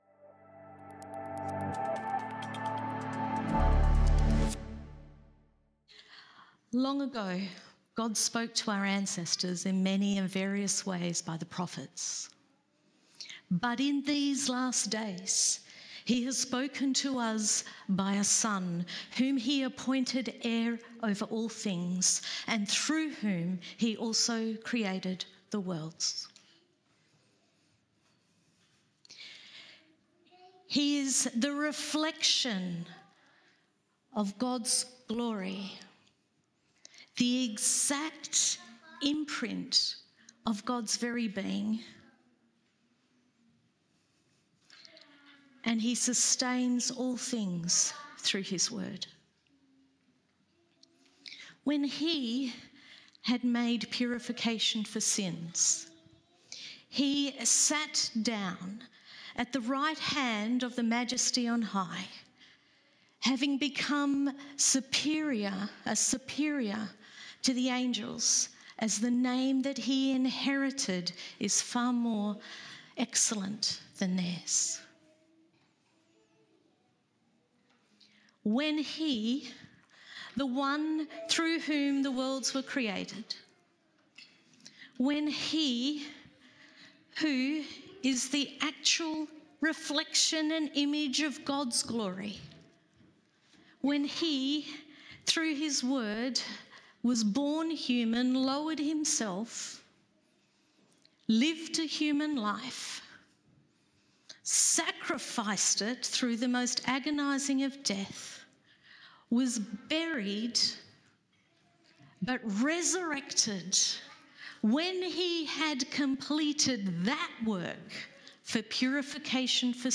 Sermons - Como Baptist Church